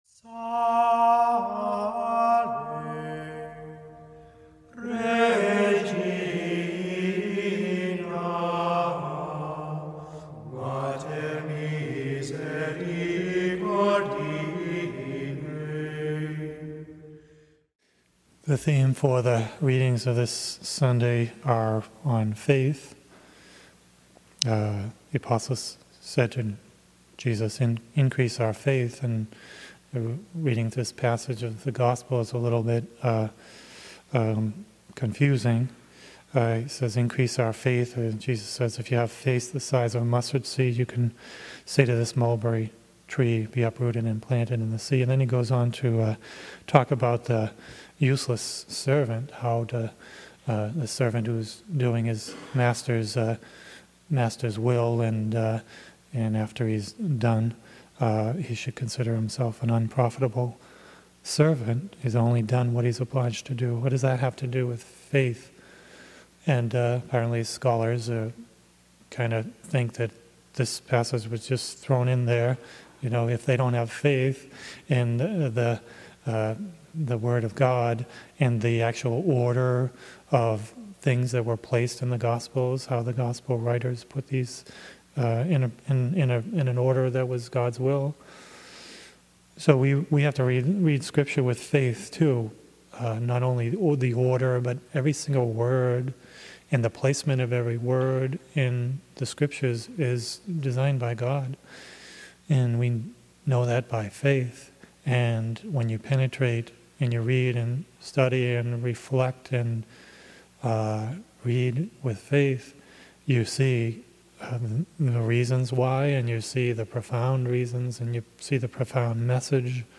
Mass: 27th Sunday in Ordinary Time - Sunday Readings: 1st: hab 1:2-3, 2:2-4 Resp: psa 95:1-2, 6-7, 8-9 2nd: 2ti 1:6-8, 13-14 Gsp: luk 17:5-10 Audio (MP3) +++